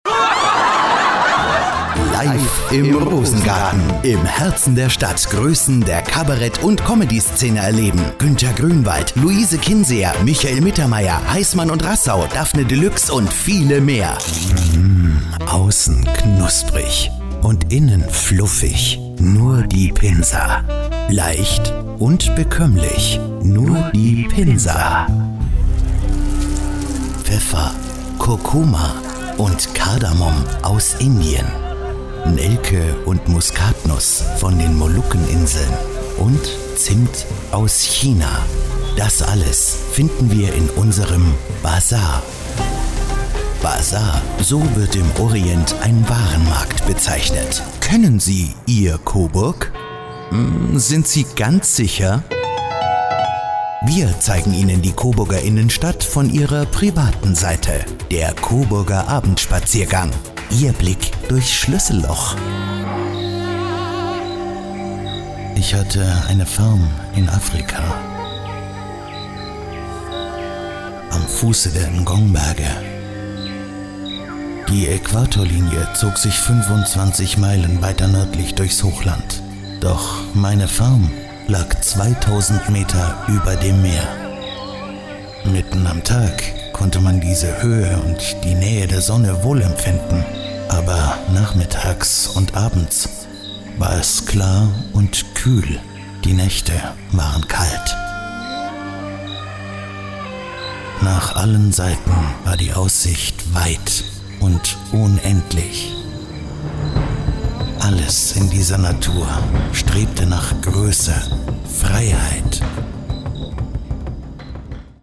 Ein kleines Medley verschiedener Genres: